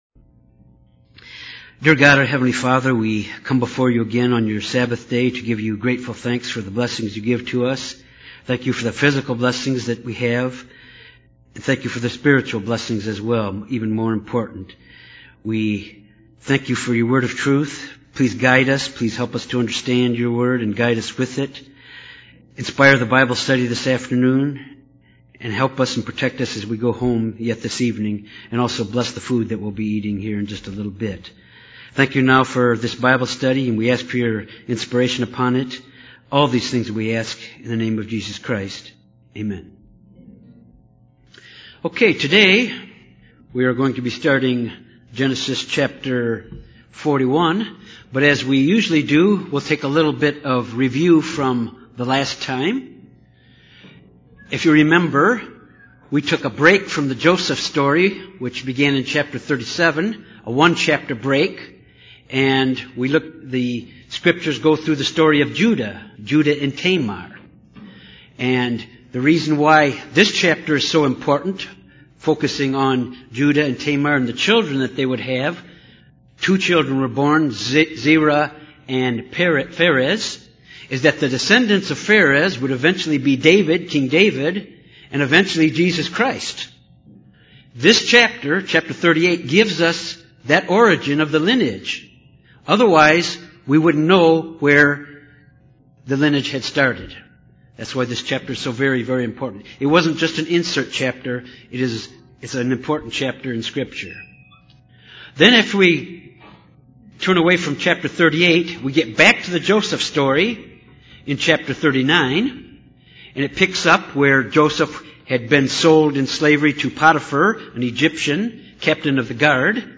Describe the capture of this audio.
Given in Little Rock, AR